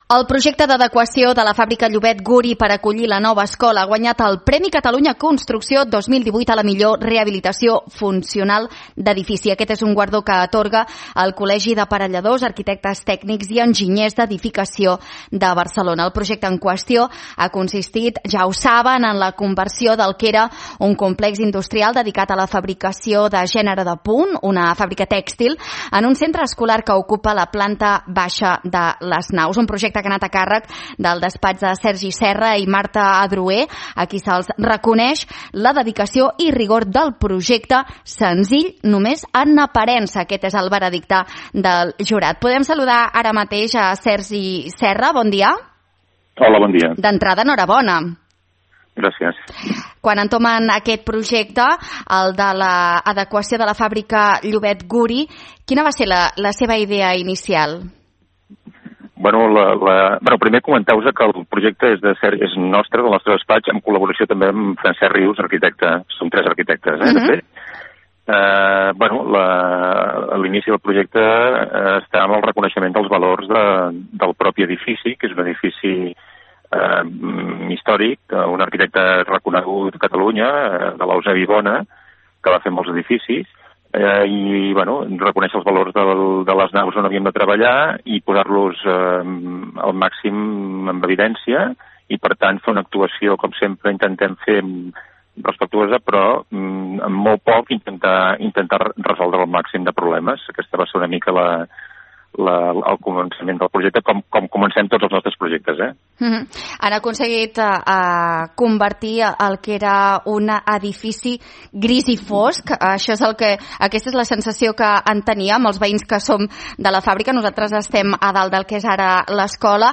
En una entrevista a l’Info Migdia